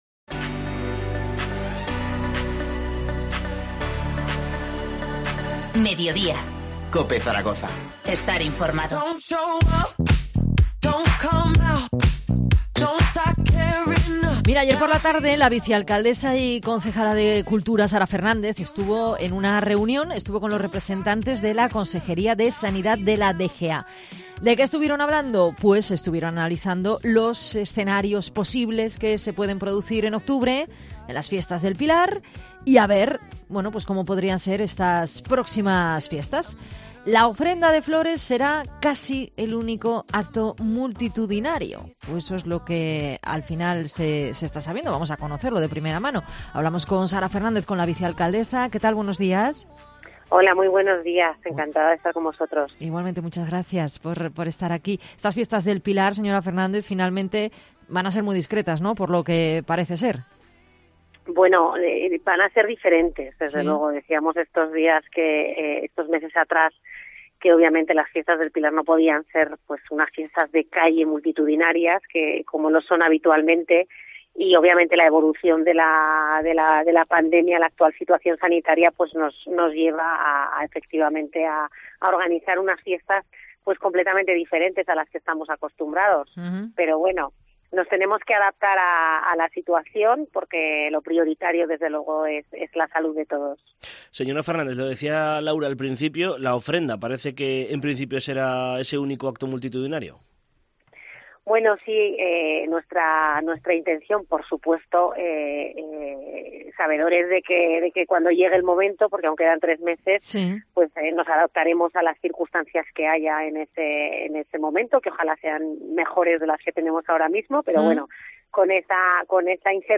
Entrevista a la vicealcaldesa de Zaragoza y responsable de Cultura, Sara Fernández 07-07-20